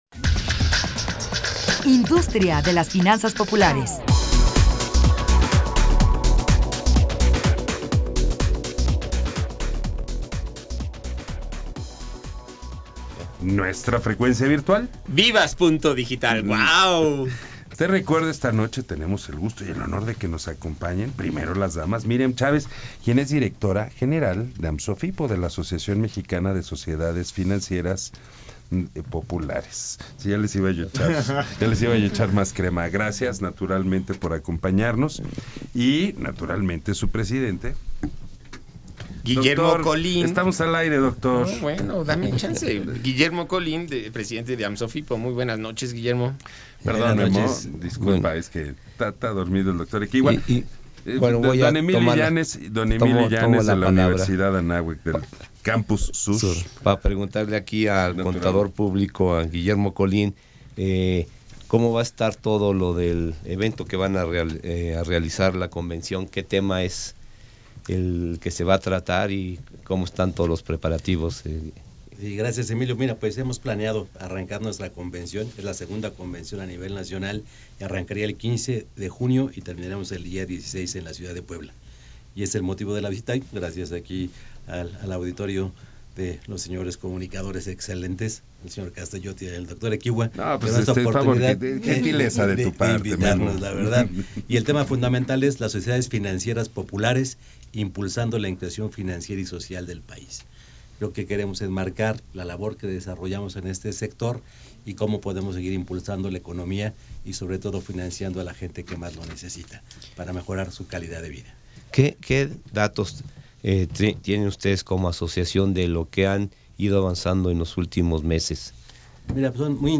entrevistaAmsofipo.mp3